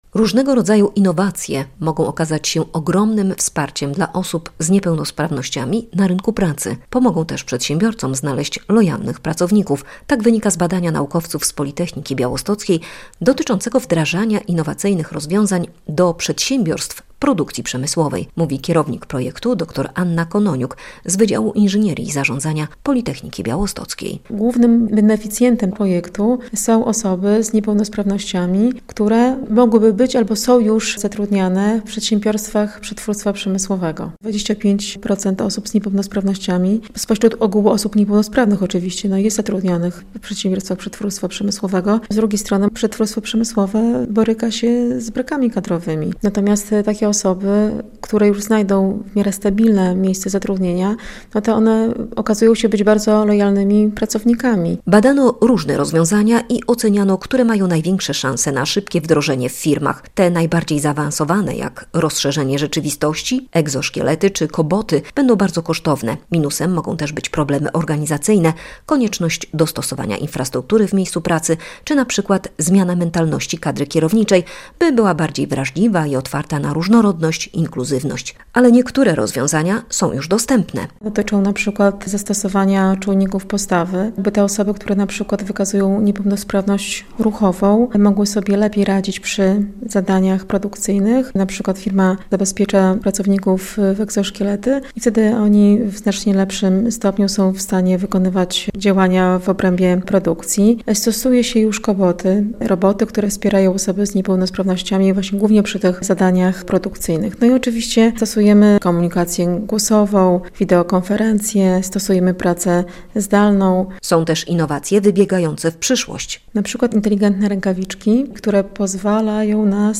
Innowacje w pracy - relacja